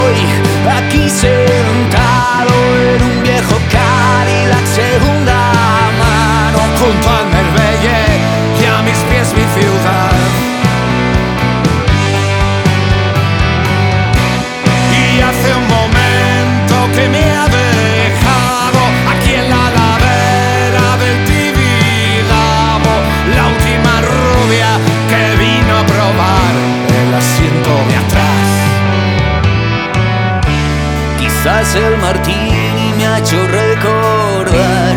Жанр: Рок